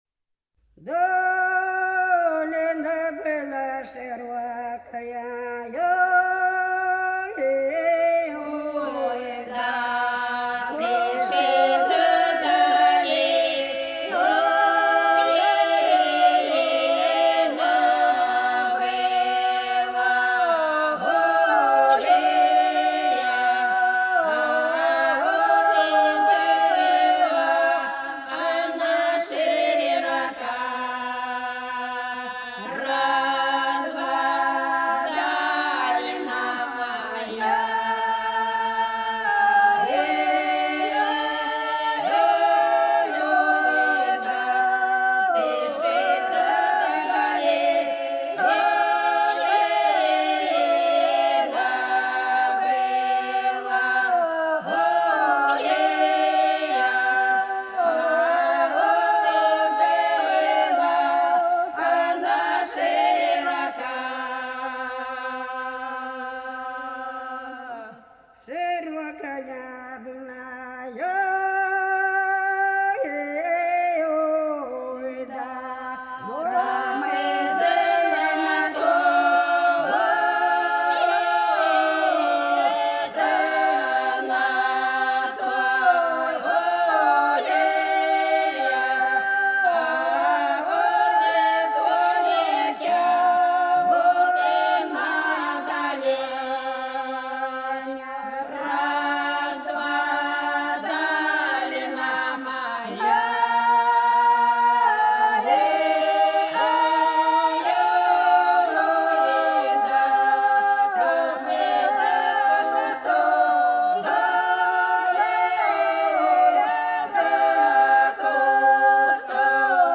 Долина была широкая (Поют народные исполнители села Нижняя Покровка Белгородской области) Долина была широкая - протяжная